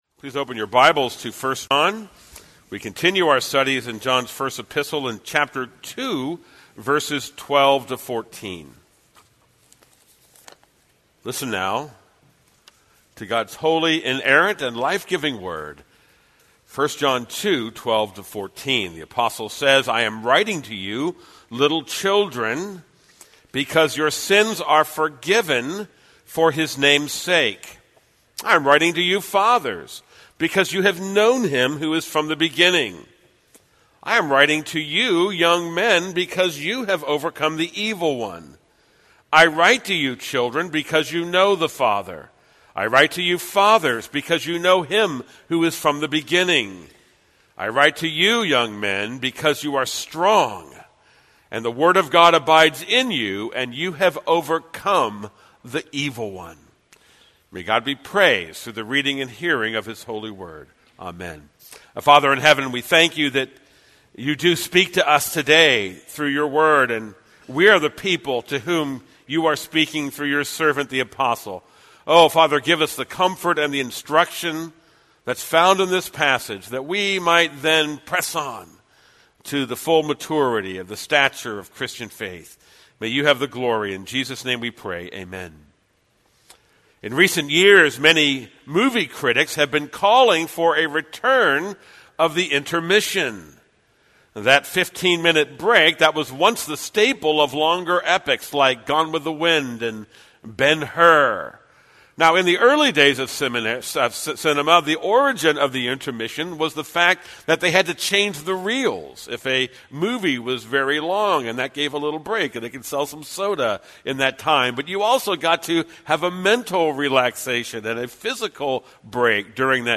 This is a sermon on 1 John 2:12-14.